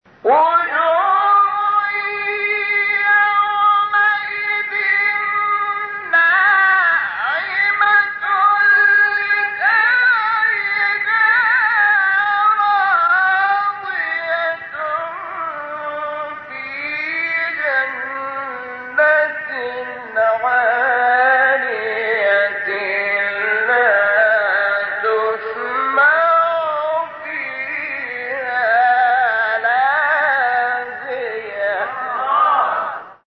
سوره : غاشیه آیه: 8-11 استاد : شحات محمد انور مقام : سه گاه قبلی بعدی